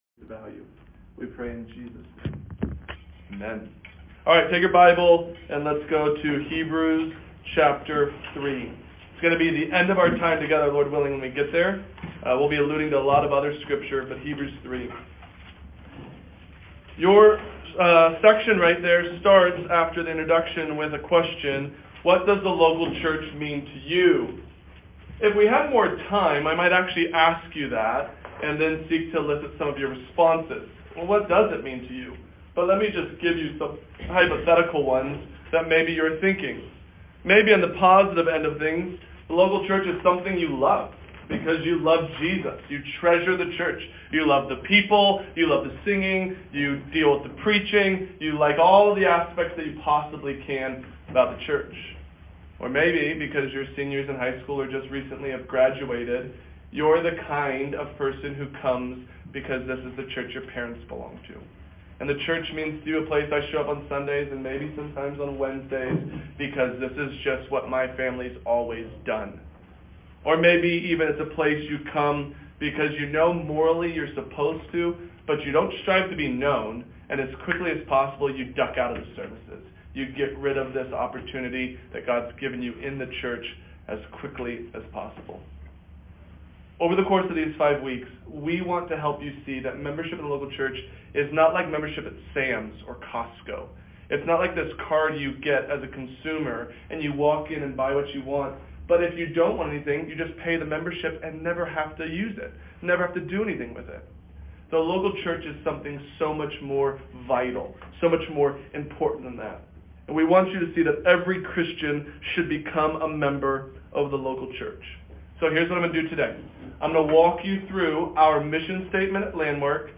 This is just a small collection of resources for study and reference. articles on confessions and creeds LISTEN TO THE LECTURE ON WHAT IS A CHURCH AGAIN, HERE!